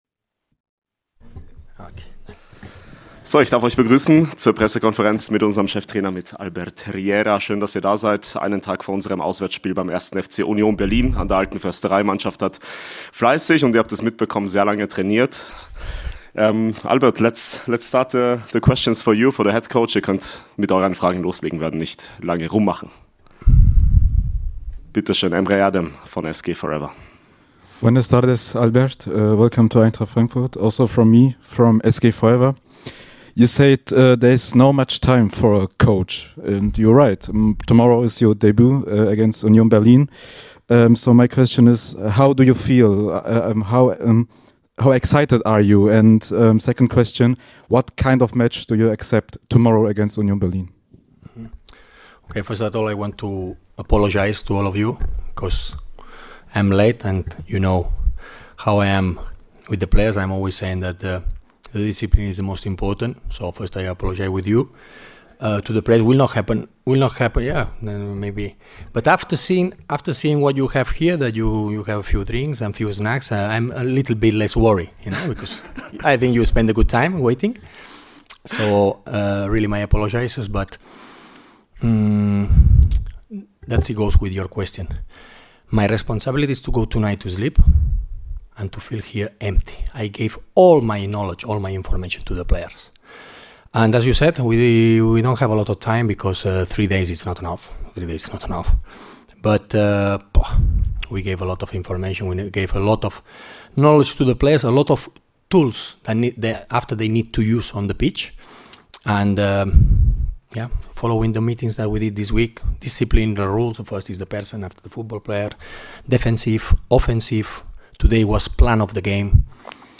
Die Pressekonferenz vor unserem Bundesliga-Auswärtsspiel in der Hauptstadt mit dem neuen Cheftrainer.